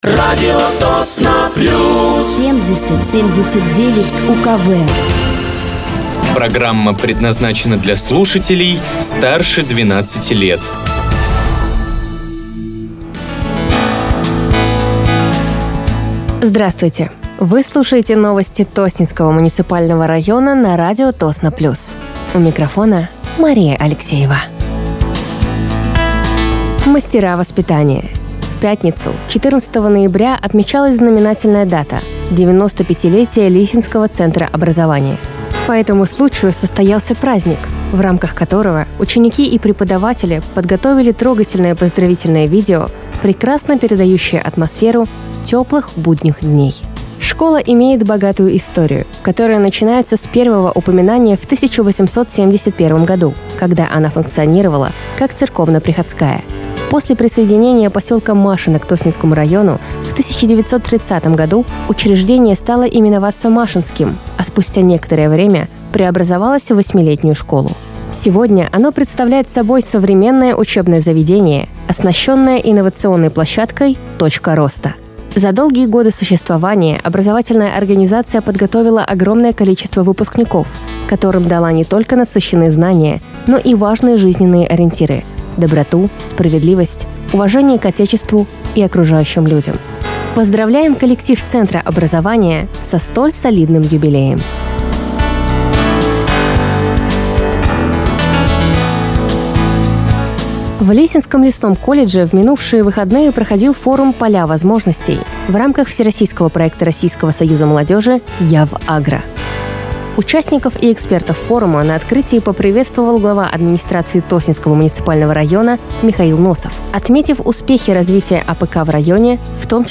Выпуск новостей Тосненского муниципального района от 19.11.2025
Вы слушаете новости Тосненского муниципального района на радиоканале «Радио Тосно плюс».